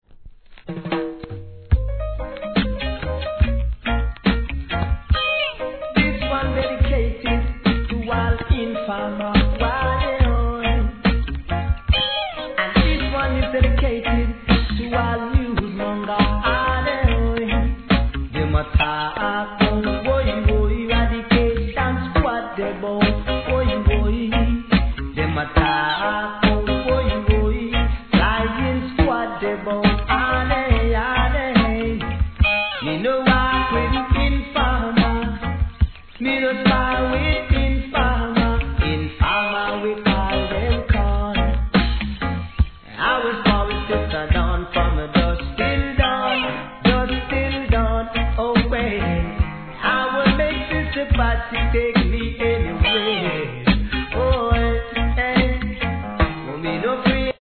REGGAE
彼のSING Jヴォイスは肩の力を入れず聴き入れます♪